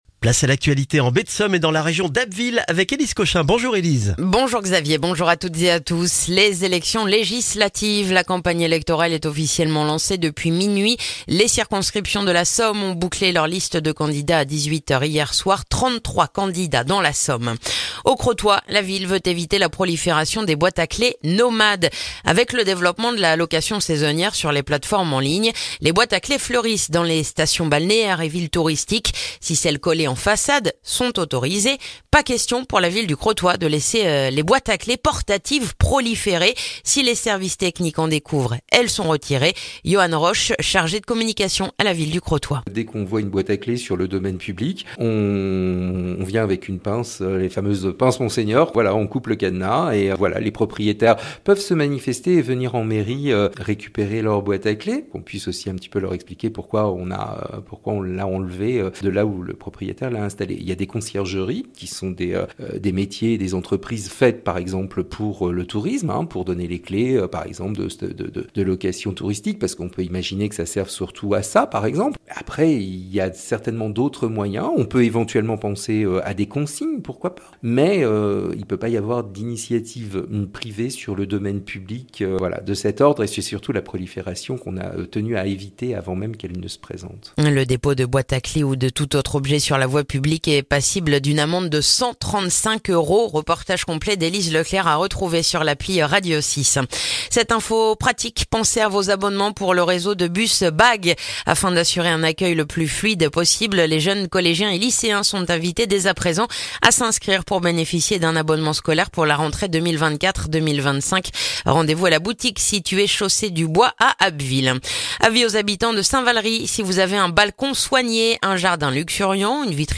Le journal du lundi 17 juin en Baie de Somme et dans la région d'Abbeville